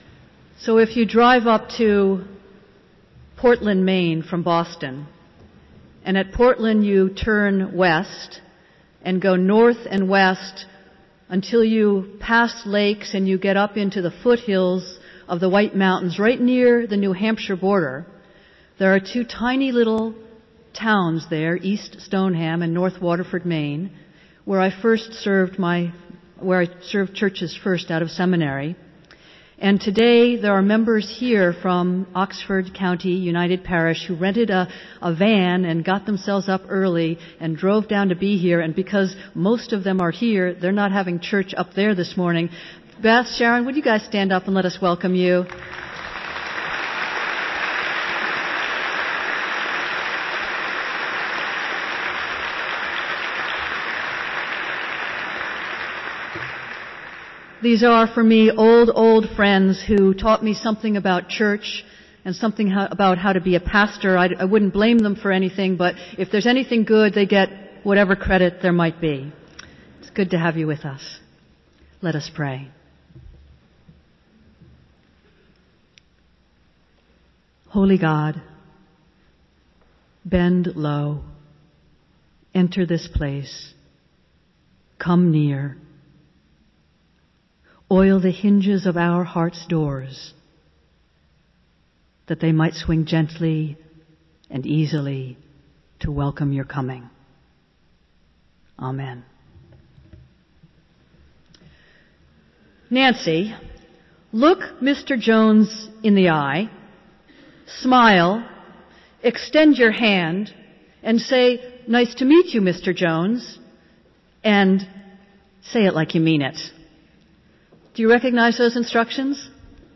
Festival Worship - Hymn Festival Sunday